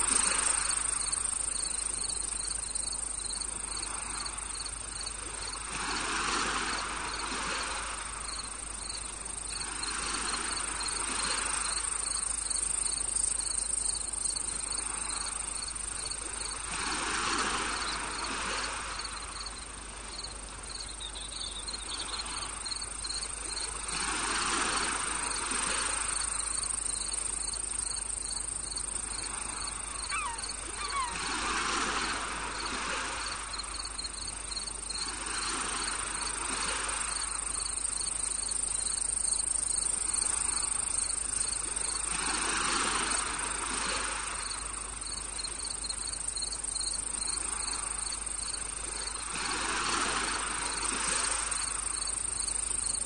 Tropical Beach At Sunset (1 Hour)
Nature sounds are recorded & designed to help people sleep, allowing you to relax and enjoy the sounds of nature while you rest or focus, with no adverts or interruptions.
Perfect for their masking effects, they are also helpful for people suffering with tinnitus.
Tropical-Beach-At-Sunset-Sample.mp3